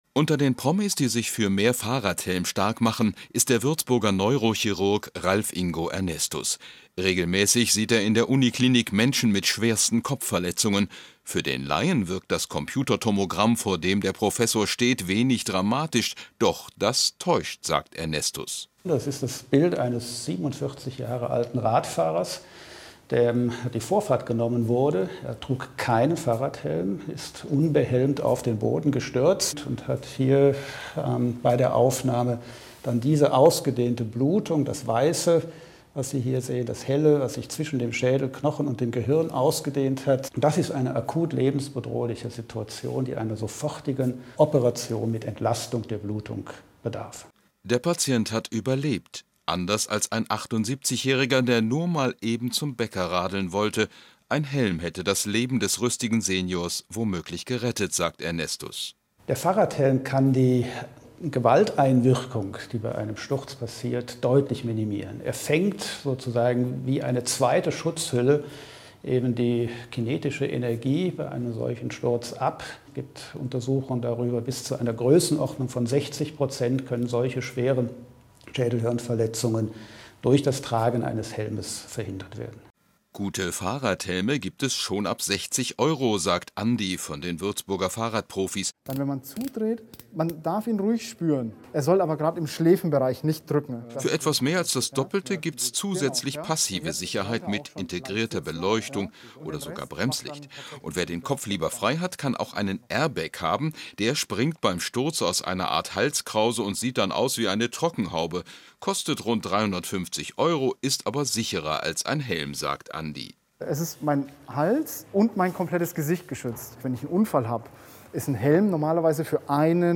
Audiodatei und Videodatei des BR-Beitrags